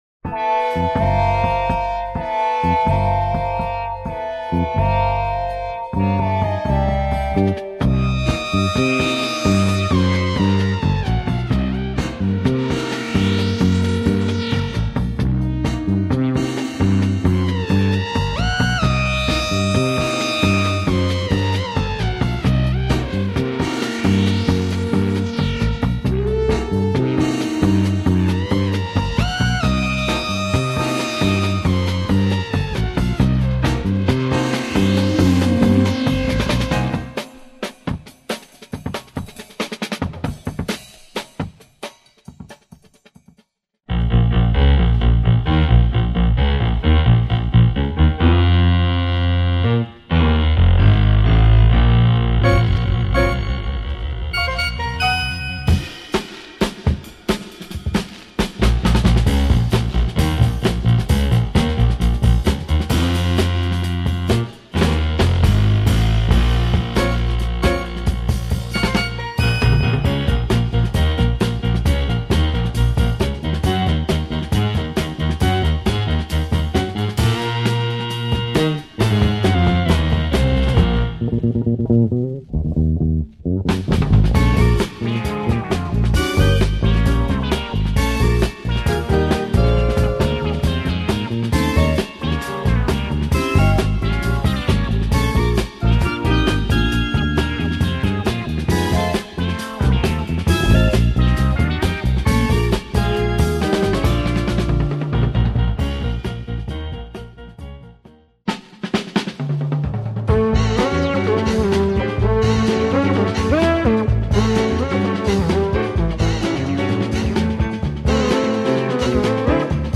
Killer electronics & beats !